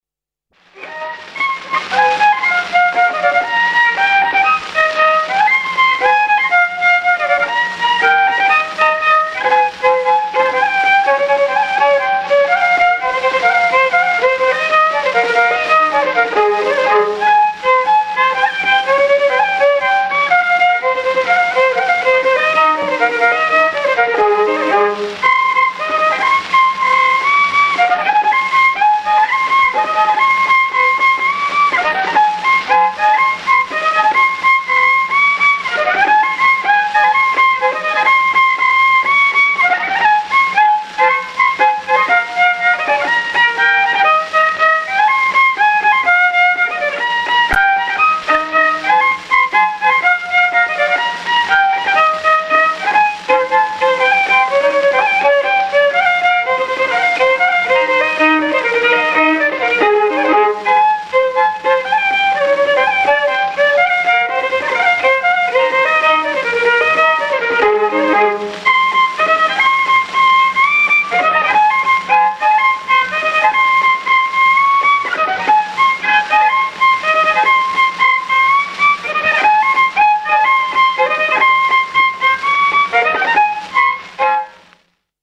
02 Madarmae Juhani polka.mp3